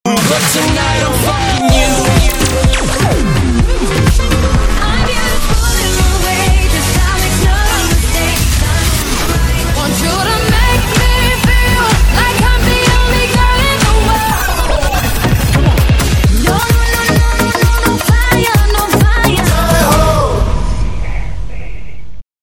Подложка для джингла (1)